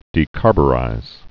(dē-kärbə-rīz, -byə-)